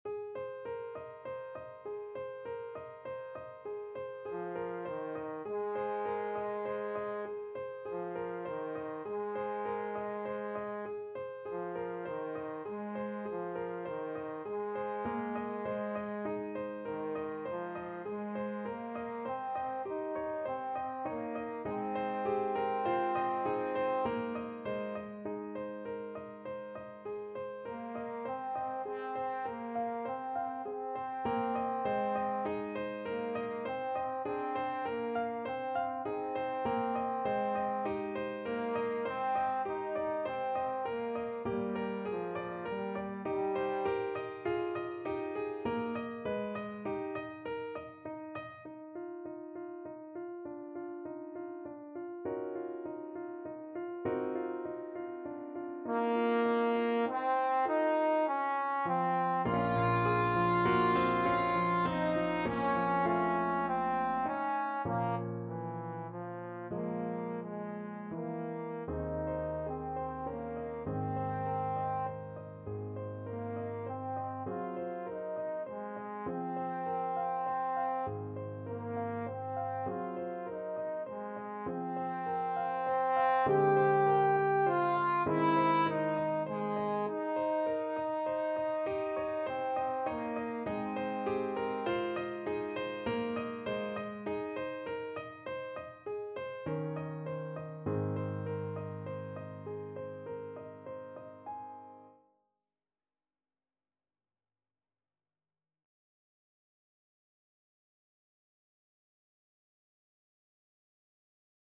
Trombone version
9/4 (View more 9/4 Music)
~ = 120 Allegretto
Eb4-G5
Classical (View more Classical Trombone Music)